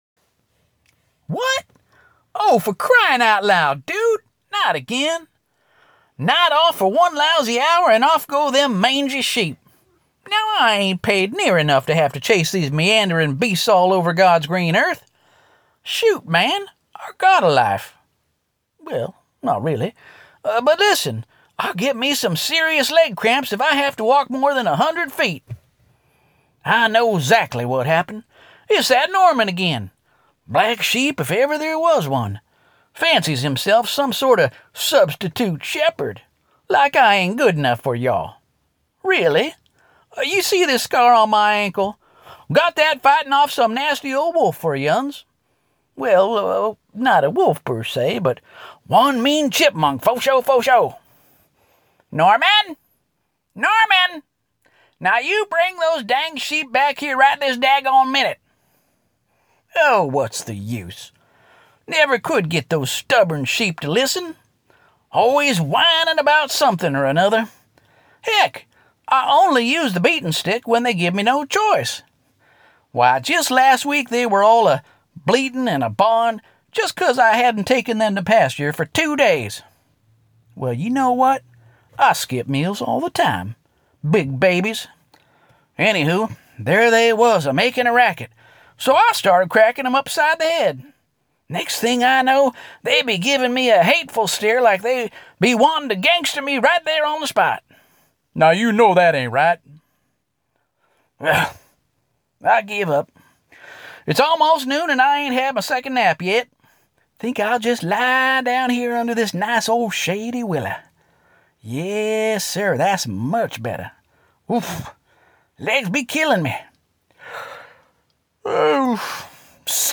You sure put on a convincing accent of a simple and ill- tempered shepherd ha ha.